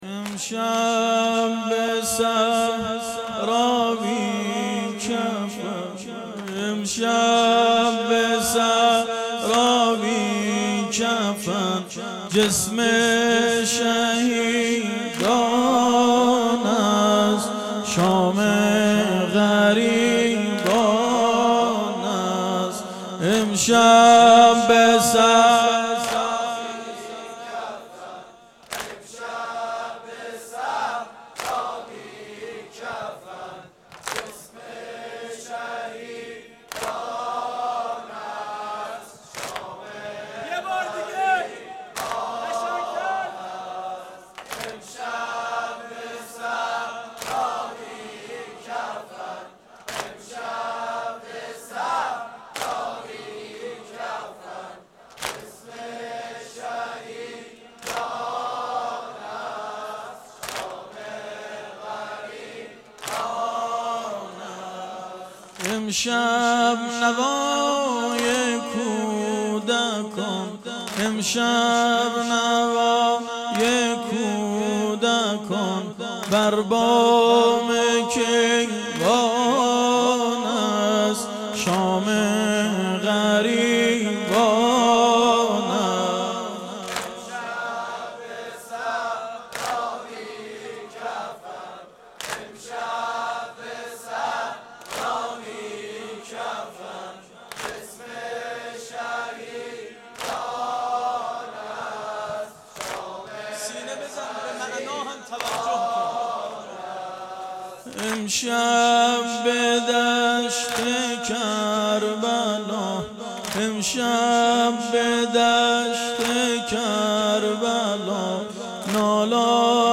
واحد سنتی شب یازدهم محرم 98